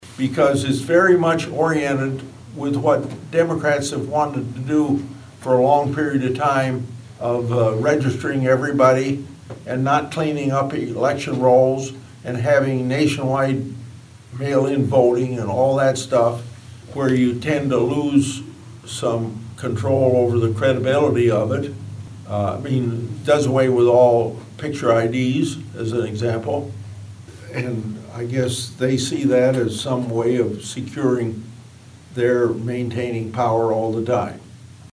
Grassley made his comments Tuesday during a stop in Audubon as part of his annual 99-county tour.